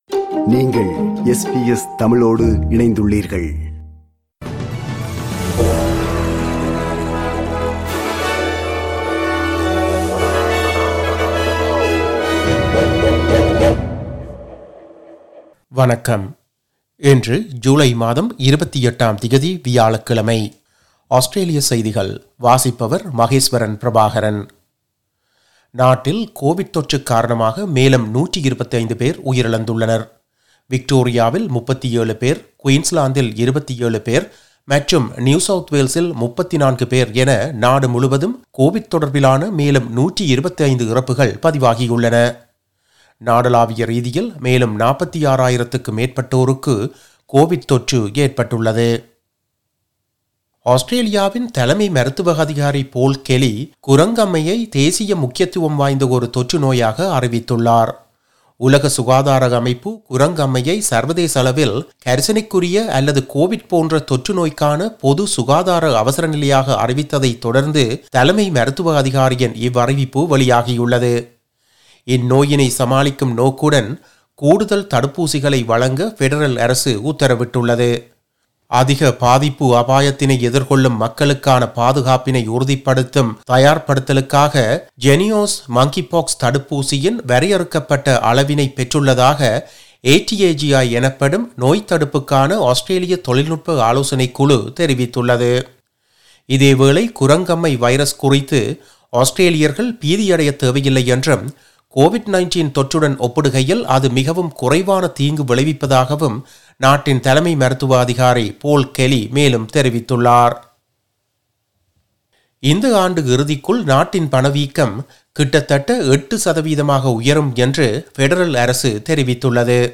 Australian news bulletin for Thursday 28 July 2022.